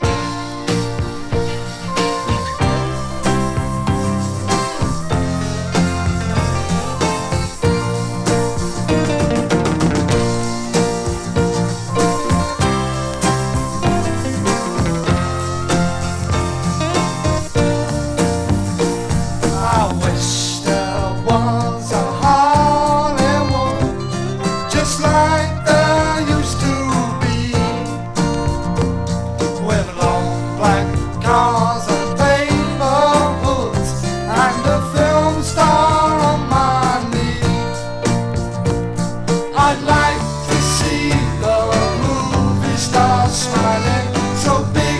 Rock, Psychedelic Rock　USA　12inchレコード　33rpm　Stereo